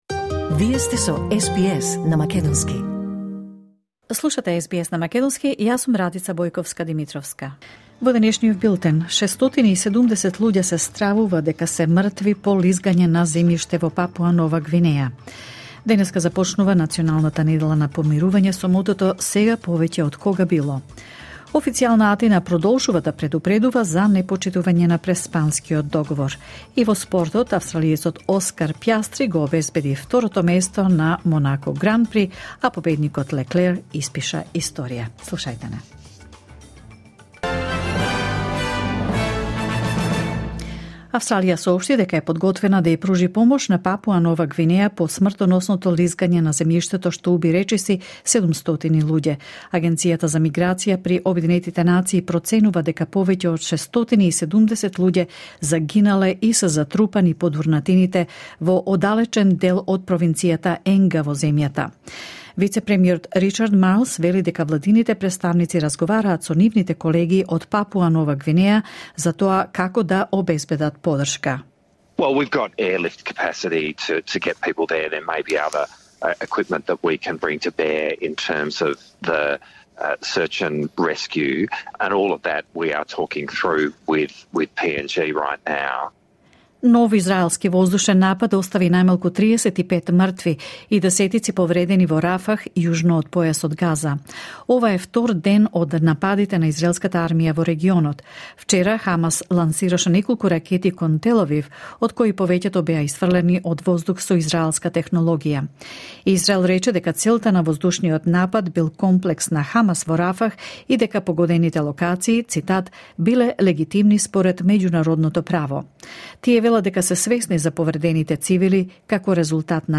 Вести на СБС на македонски 27 мај 2024
SBS News in Macedonian 27 May 2024